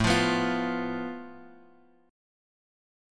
Asus2#5 chord {5 8 7 x x 7} chord